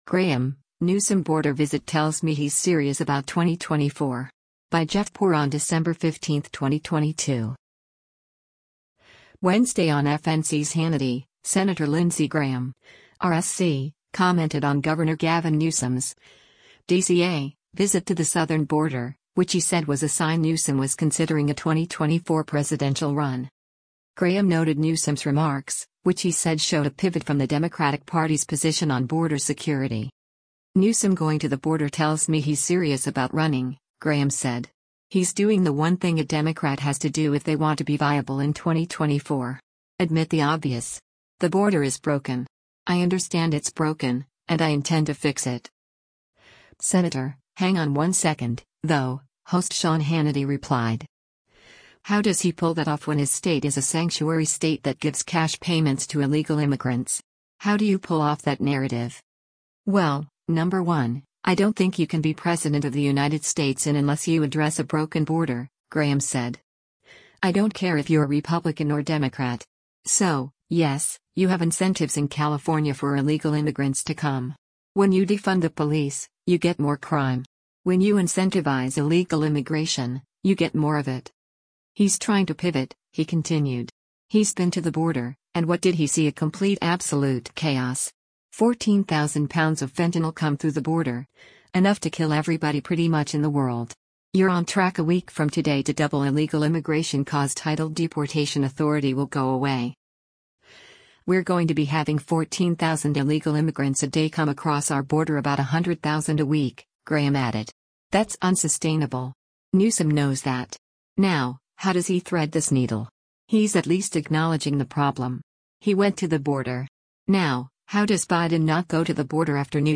Wednesday on FNC’s “Hannity,” Sen. Lindsey Graham (R-SC) commented on Gov. Gavin Newsom’s (D-CA) visit to the southern border, which he said was a sign Newsom was considering a 2024 presidential run.